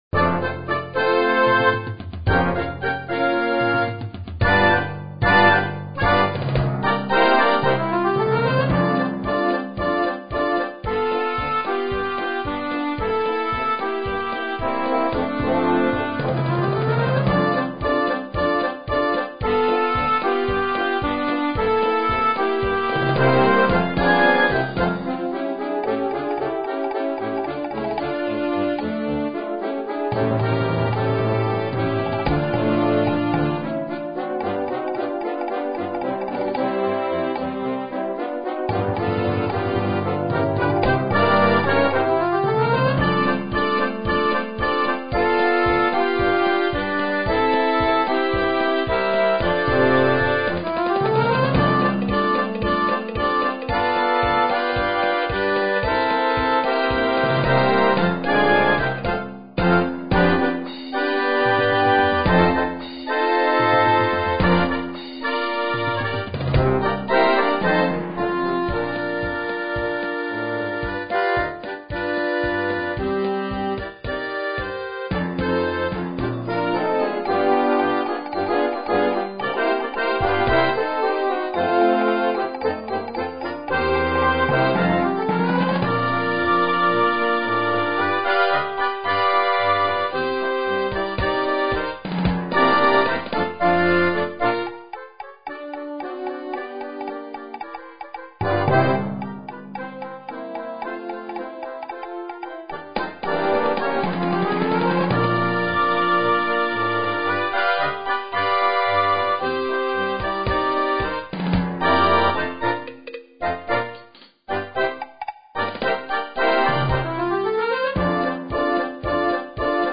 Brano da concerto
Un piacevole brano da inserire in un concerto estivo.